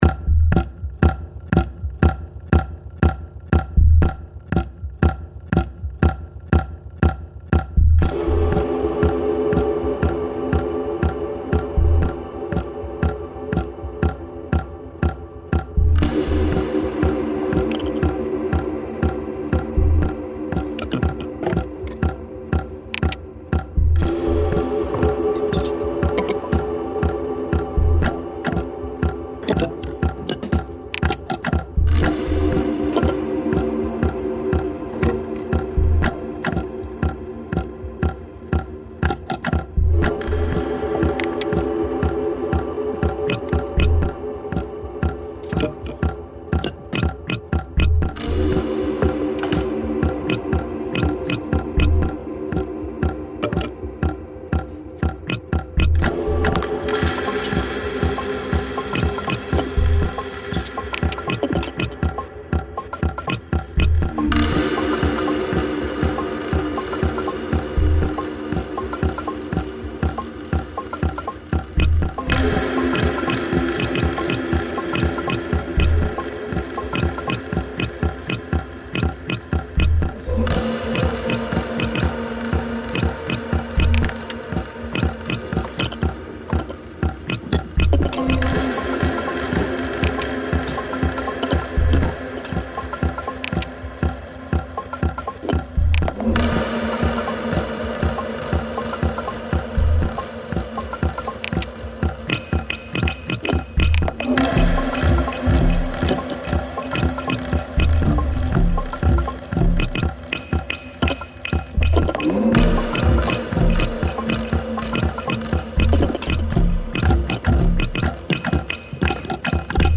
the haunting melody of the vocals and panpipes